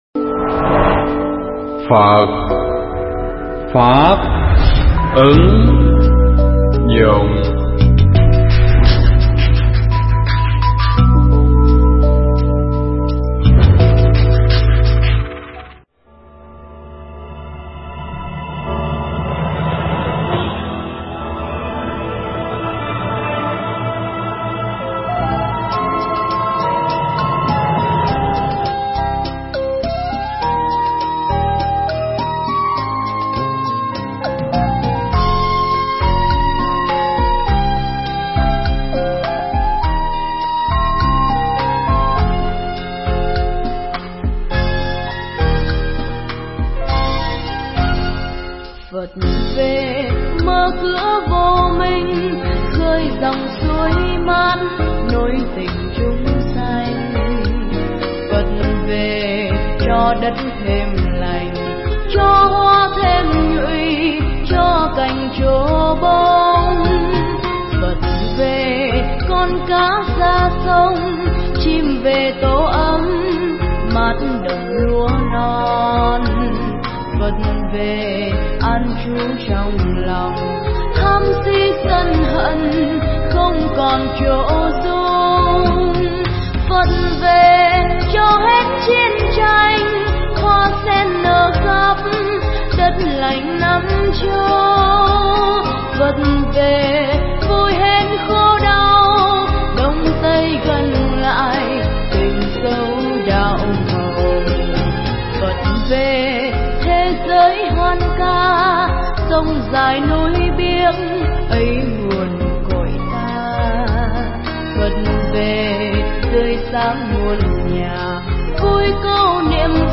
Nghe Mp3 thuyết pháp Nhận Thức Đức Phật Trong Thời Hiện Đại